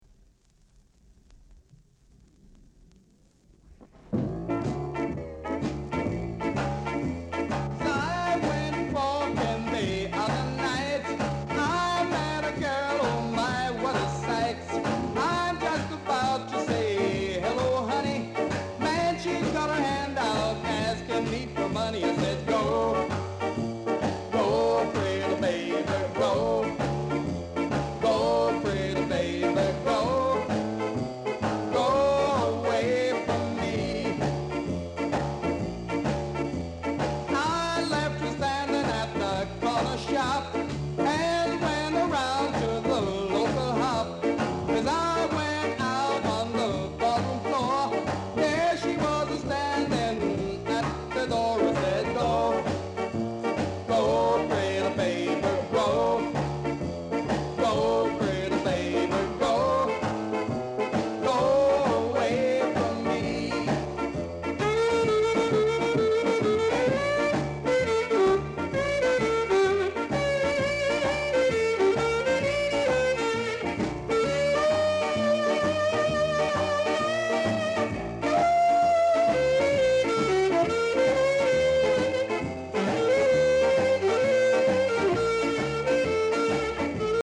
Ska Male Vocal
nice Ja r&b vocal w-sider!